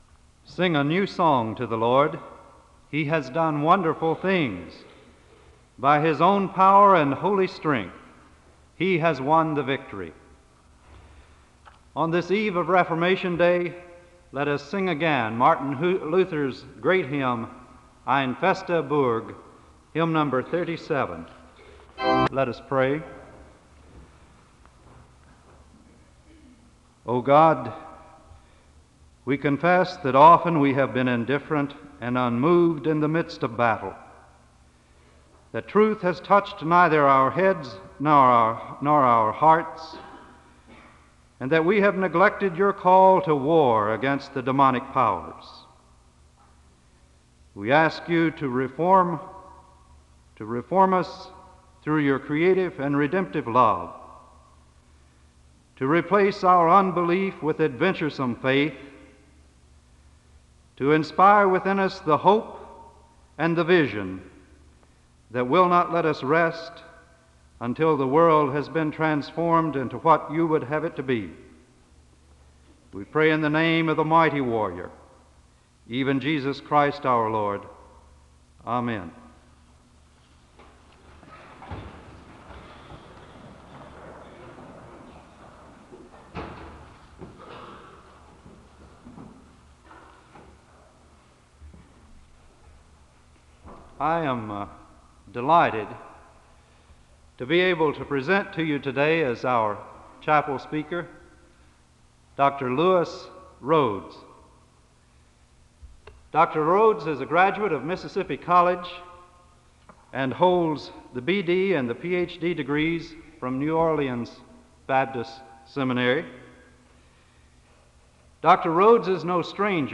The service opens with a benediction and prayer (00:00-01:19).
The choir leads in a song of worship (04:40-07:55).
SEBTS Chapel and Special Event Recordings SEBTS Chapel and Special Event Recordings